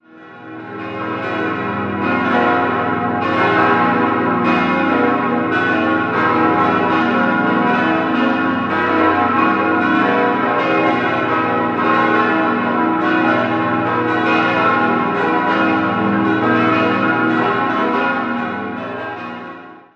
5-stimmiges Gloria-TeDeum-Geläute: gis°-h°-cis'-e'-fis' Die Glocken wurden im Jahr 1934 von der Gießerei Rüetschi in Aarau hergestellt.